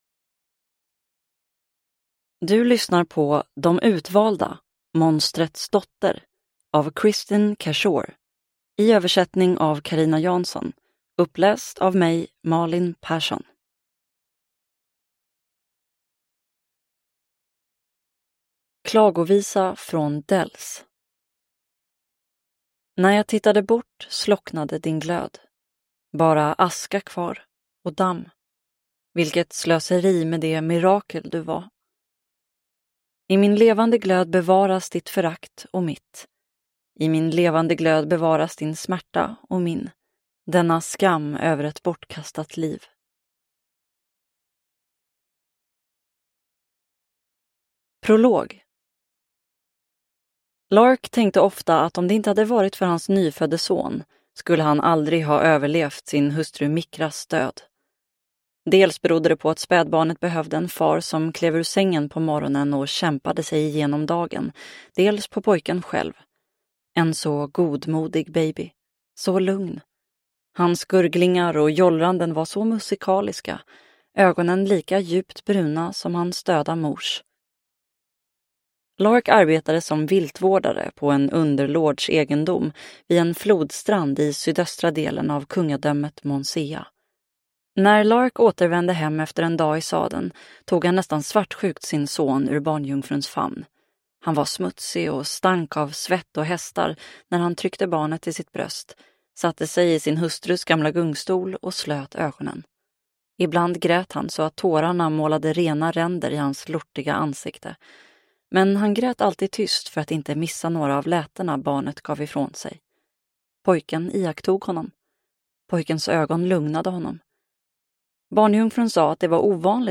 Monstrets dotter – Ljudbok – Laddas ner